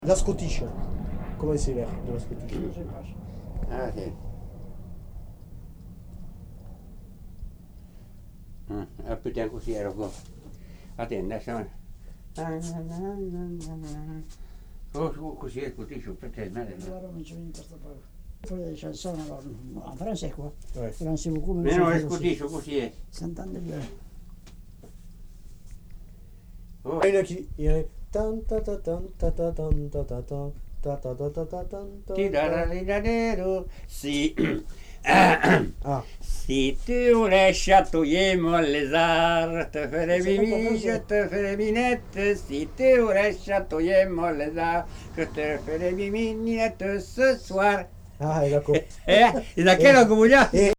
Aire culturelle : Lauragais
Lieu : Villaudric
Genre : chant
Effectif : 1
Type de voix : voix d'homme
Production du son : chanté
Danse : scottish